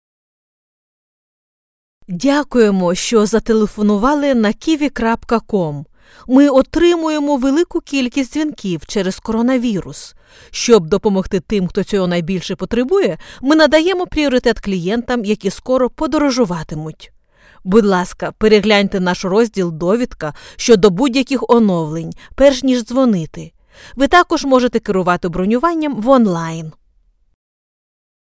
Ukrainian Language IVR Showreel
Female
Friendly
Smooth
Soft
Warm